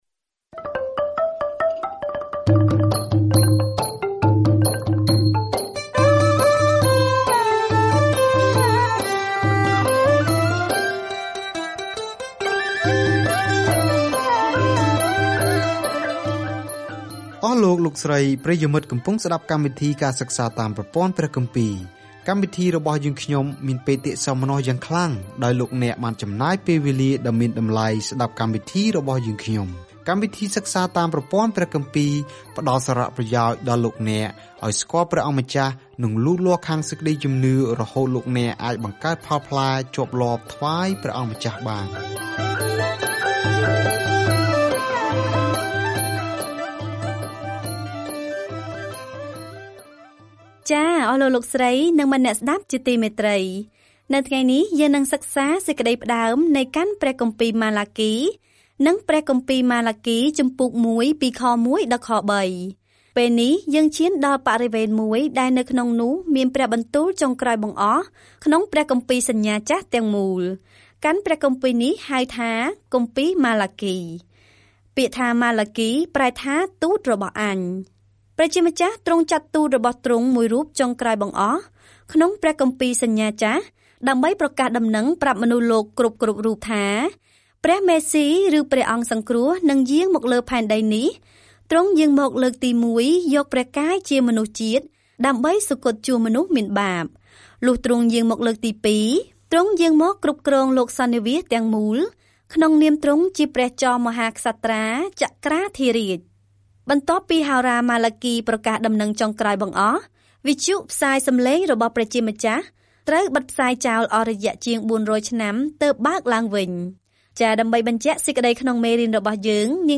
ម៉ាឡាគីរំឭកជនជាតិអ៊ីស្រាអែលដែលផ្តាច់ទំនាក់ទំនងថាគាត់មានសារពីព្រះមុនពេលពួកគេស៊ូទ្រាំនឹងភាពស្ងៀមស្ងាត់ដ៏យូរ - ដែលនឹងបញ្ចប់នៅពេលដែលព្រះយេស៊ូវគ្រីស្ទចូលដល់ឆាក។ ការធ្វើដំណើរជារៀងរាល់ថ្ងៃតាមរយៈម៉ាឡាគី នៅពេលអ្នកស្តាប់ការសិក្សាជាសំឡេង ហើយអានខគម្ពីរដែលជ្រើសរើសពីព្រះបន្ទូលរបស់ព្រះ។